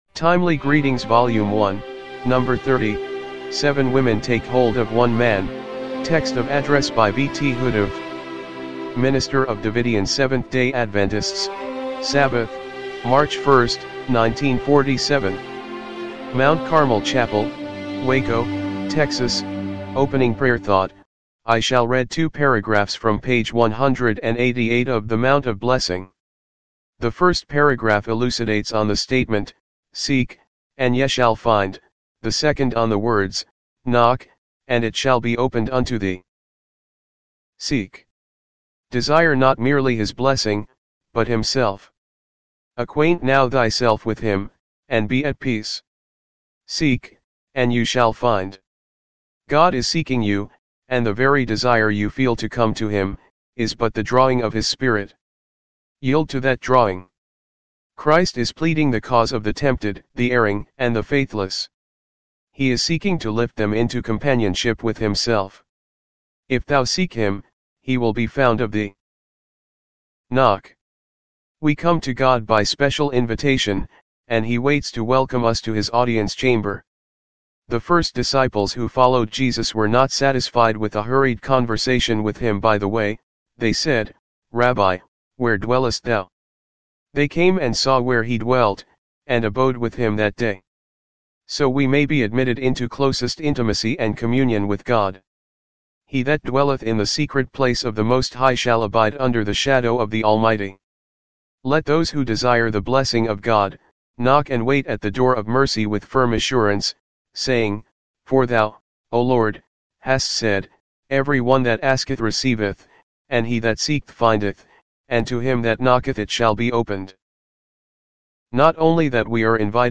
timely-greetings-volume-1-no.-30-mono-mp3.mp3